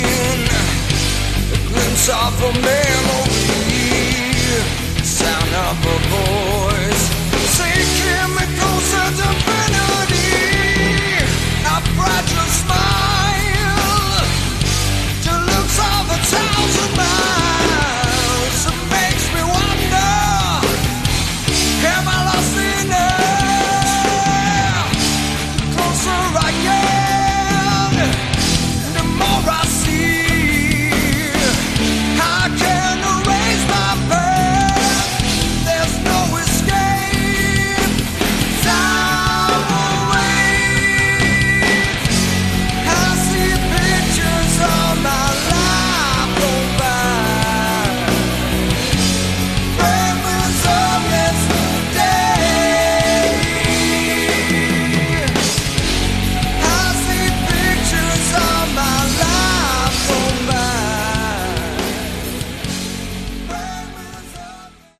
Category: Melodic Prog Rock
electric and acoustic guitars
keyboards, backing vocals
drums, backing vocals